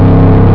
ENGINE.WAV